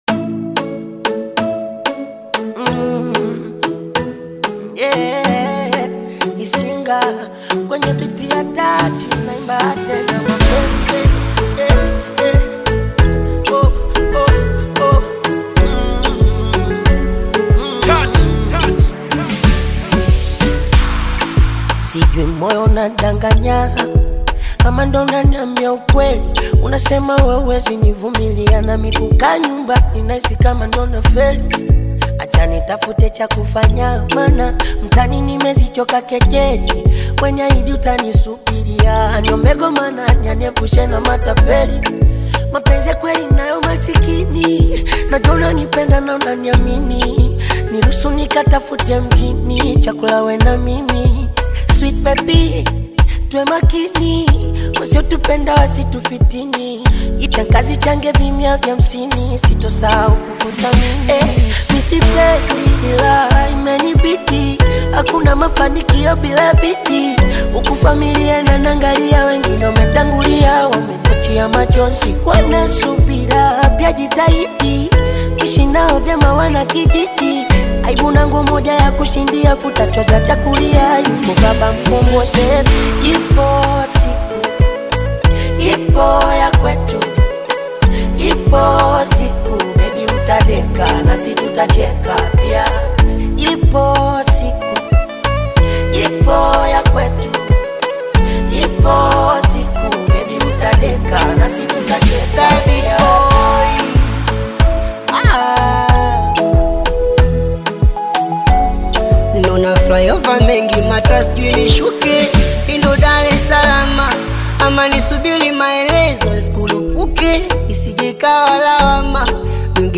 uplifting Afro-Beat/Bongo Flava single